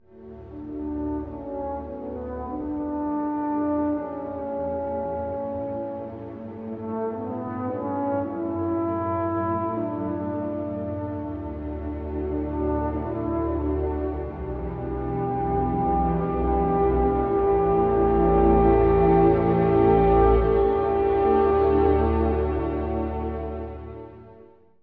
(序奏) 古い音源なので聴きづらいかもしれません！
冒頭のHr…甘美哀愁をもち、憧れを歌うかのよう。
続くObとHrによる対話は、慰めのような明るさを感じます。